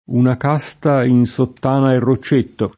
rocchetto [ rokk % tto ] s. m.